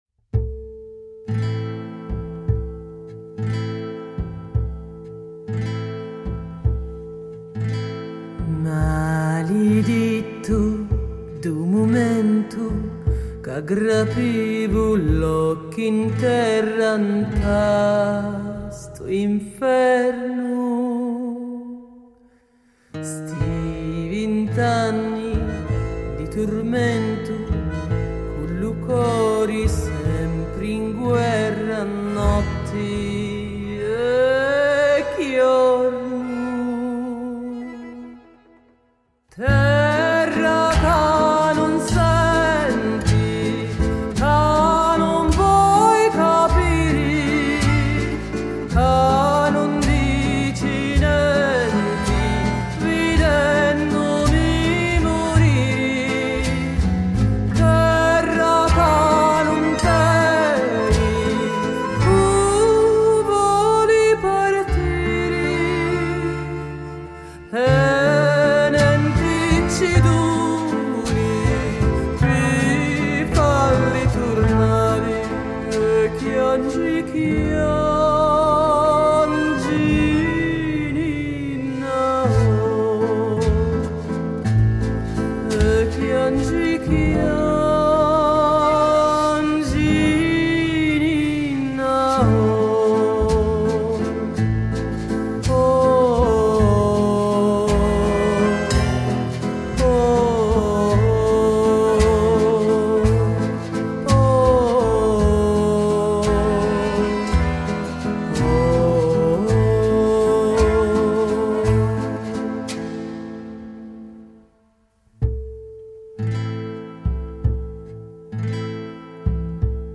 Voce solista
Pianoforte
Violino
Percussioni
Contrabbasso